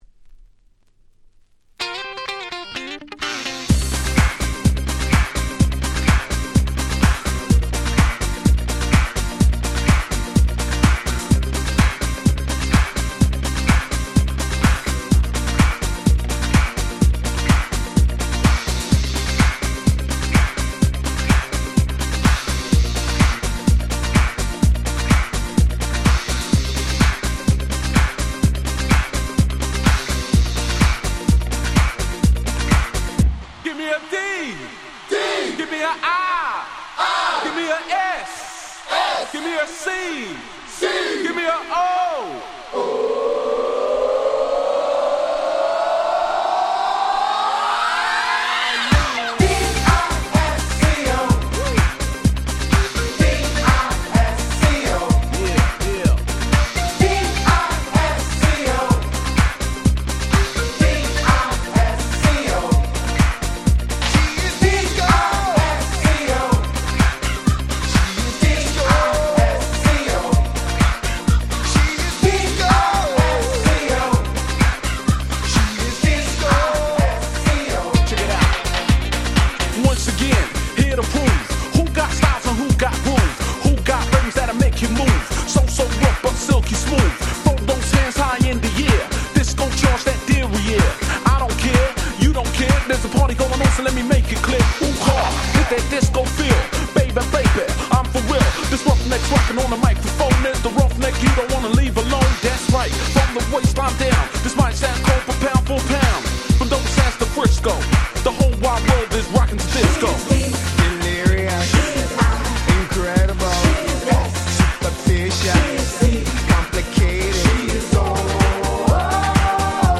97' 鉄板キャッチーDance Popクラシック！！
タイトル通りの鉄板ディスコネタの底抜けに明るくハッピーなダンスポップチューン！！
激キャッチー！！
ユーロダンス